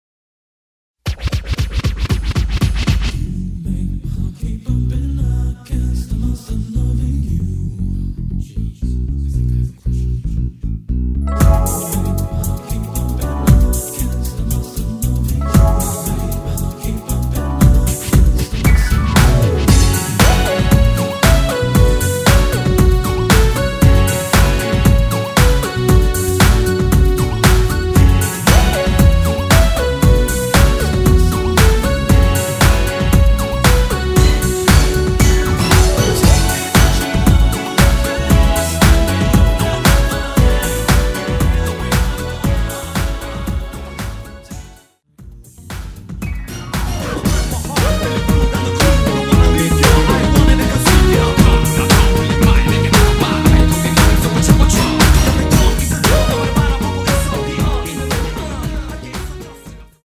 원키 코러스및 중간 랩 포함된 MR 입니다.(미리듣기 참조)
F#m
앞부분30초, 뒷부분30초씩 편집해서 올려 드리고 있습니다.
중간에 음이 끈어지고 다시 나오는 이유는